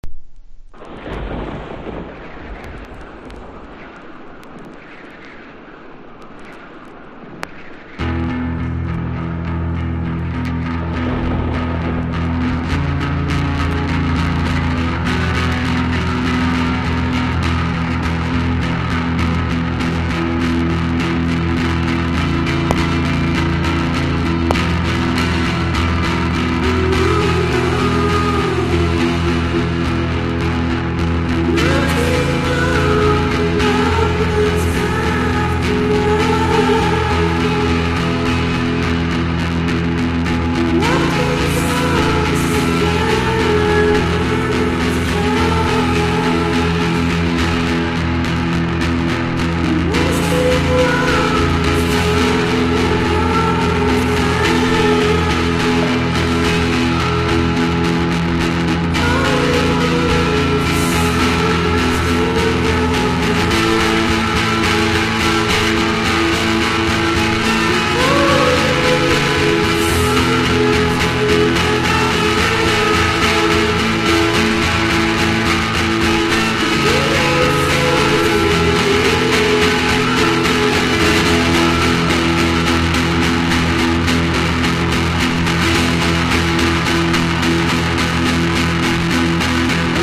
1. 00S ROCK >
SHOEGAZER / CHILLWAVE / DREAM POP